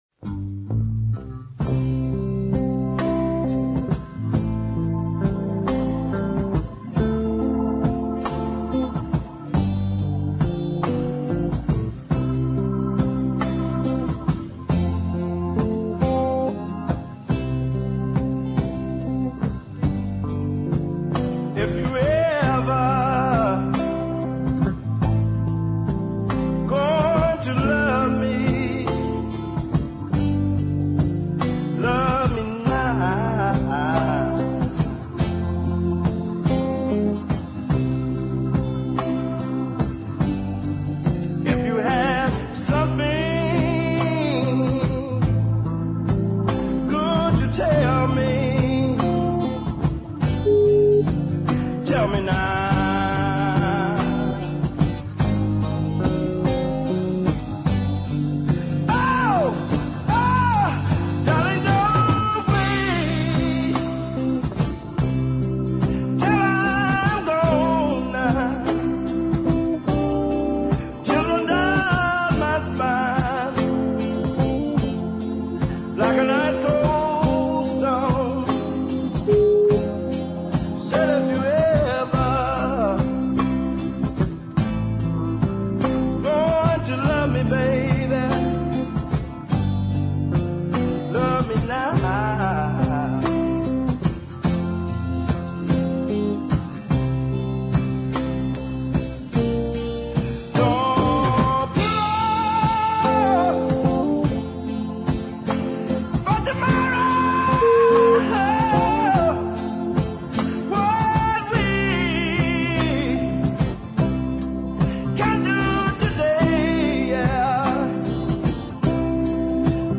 A real grower this.